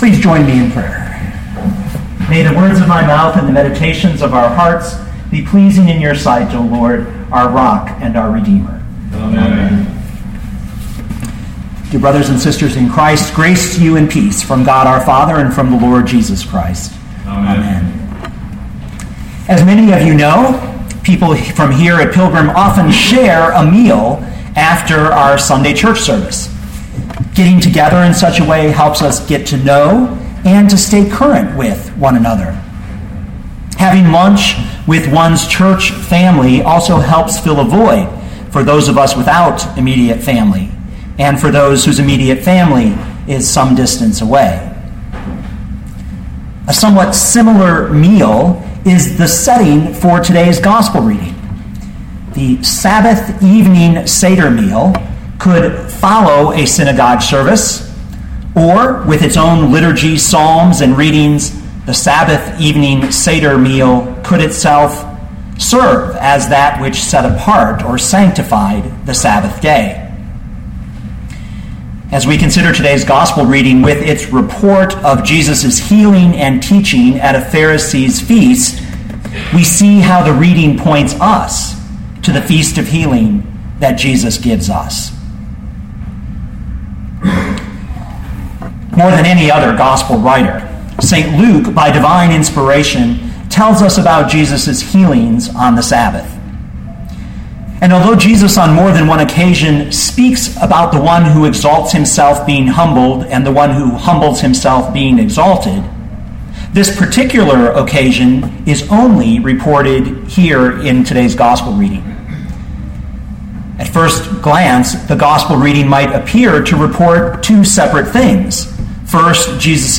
feast-of-healing.mp3